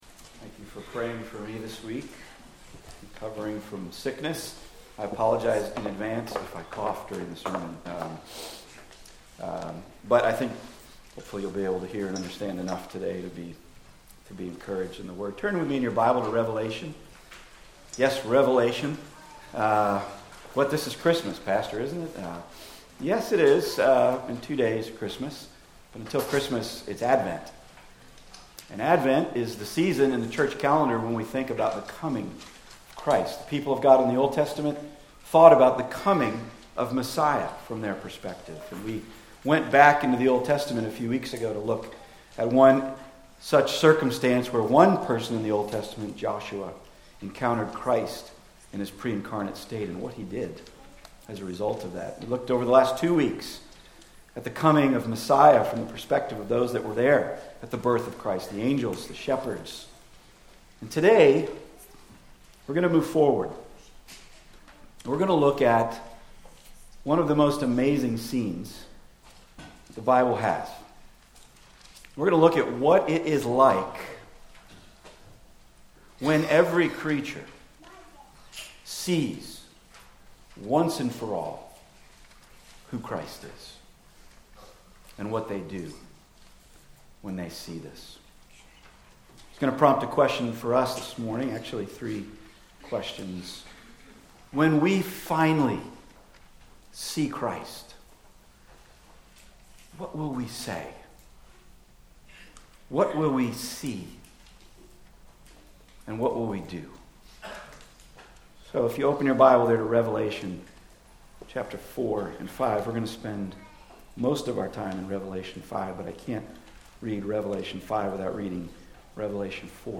Sermons – Page 70 – Trinity Church
Genesis 9:12-13 Service Type: Weekly Sunday